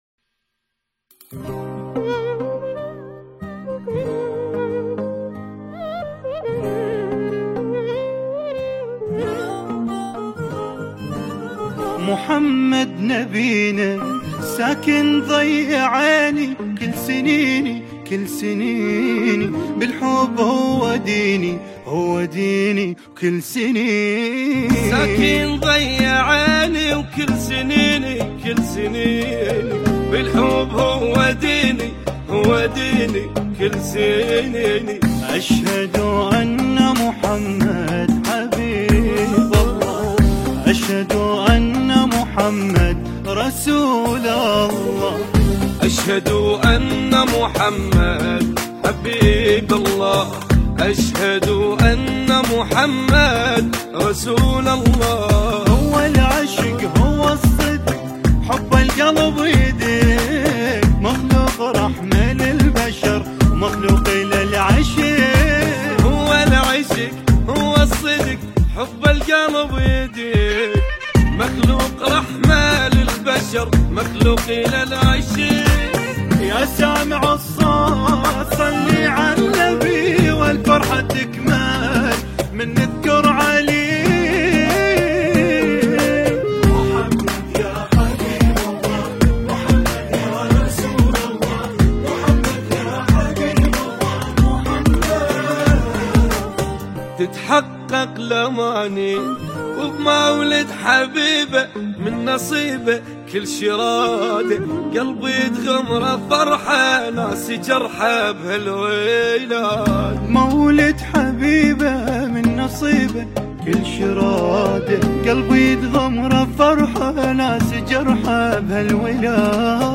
نماهنگ عربی
نواهنگ عربی